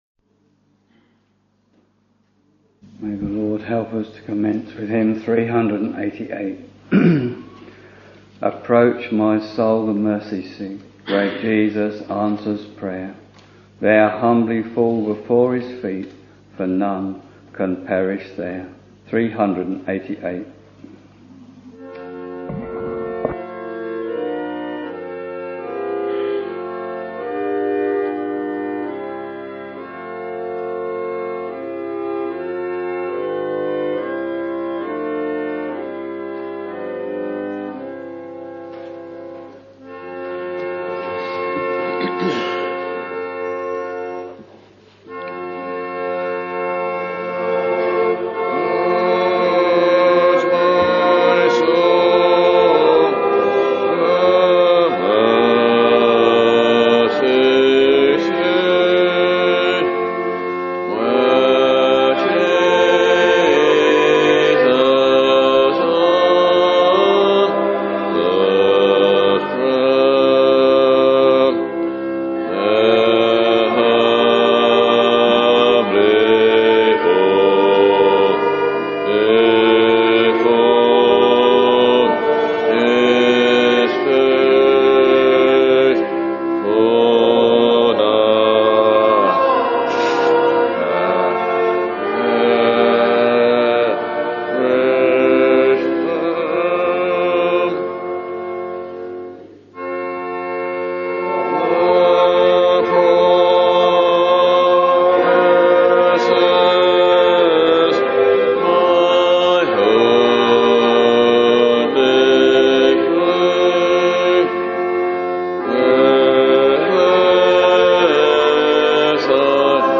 Sunday, 23rd February 2014 — Morning Service Preacher